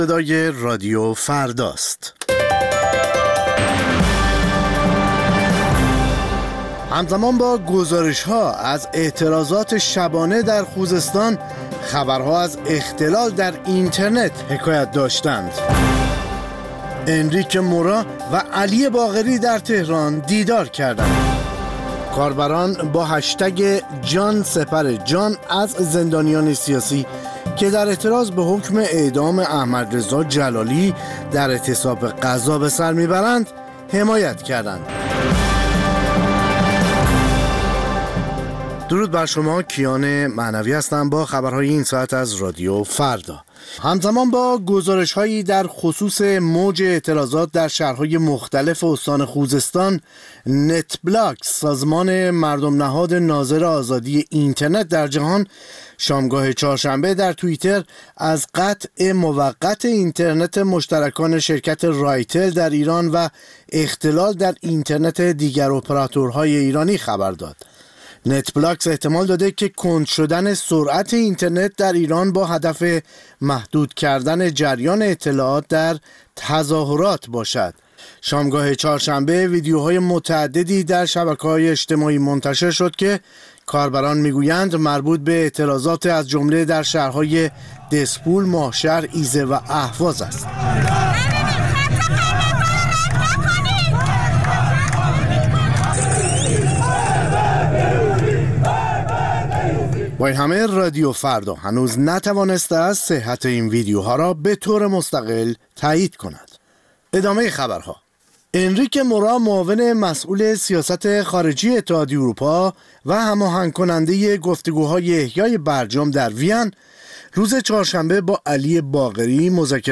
سرخط خبرها ۱۰:۰۰